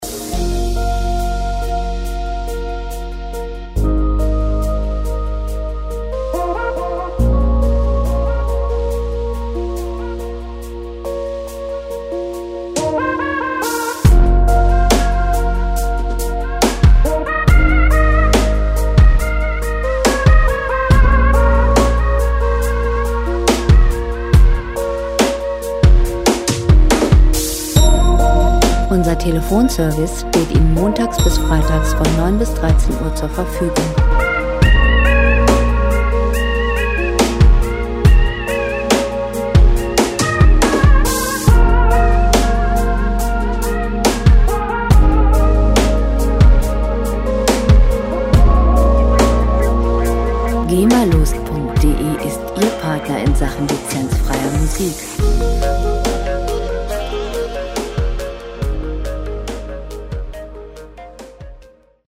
Elektronische Musik - Moderne Welt
Musikstil: Future Pop
Tempo: 70 bpm
Tonart: B-Moll
Charakter: entspannt, ruhig
Instrumentierung: Synthesizer, Klangeffekte